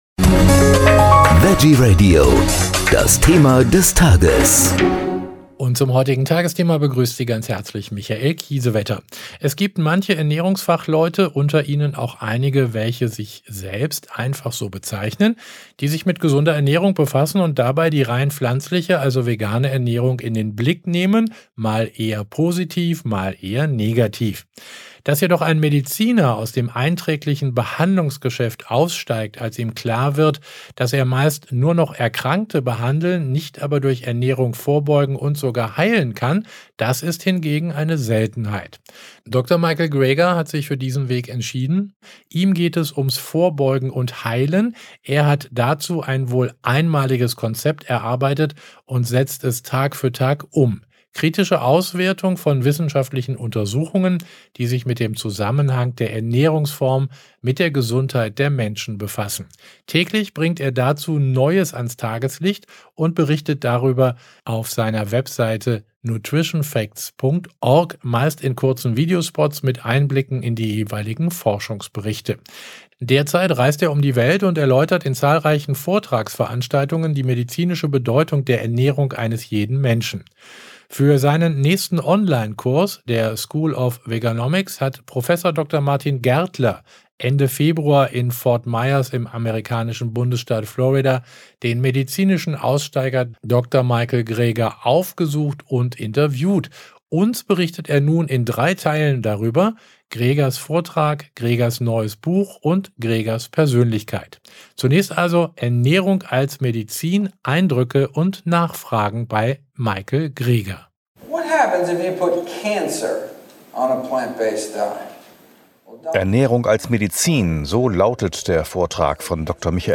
Bei einer Begegnung mit dem Mediziner Dr. Michael Greger ging es im Interview